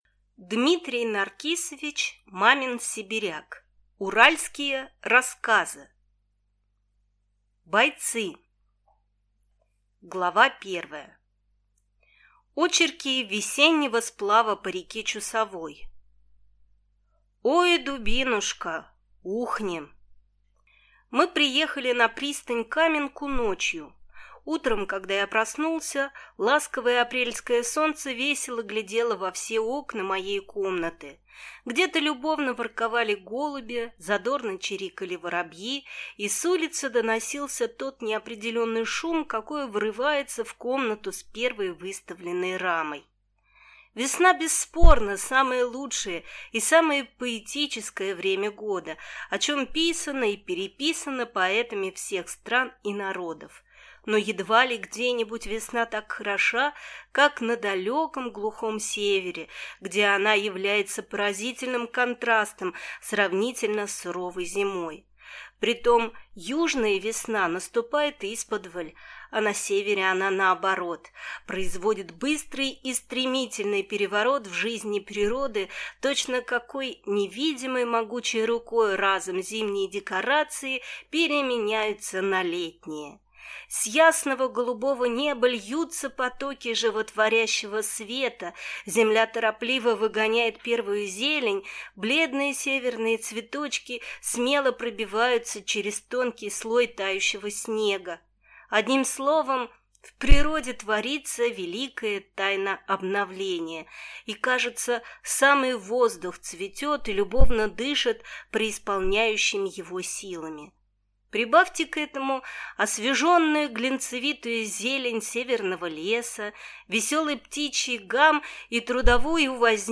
Аудиокнига Уральские рассказы